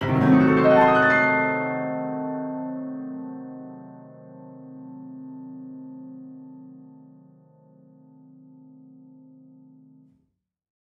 Index of /musicradar/gangster-sting-samples/Chord Hits/Piano
GS_PiChrdTrill-Cdim.wav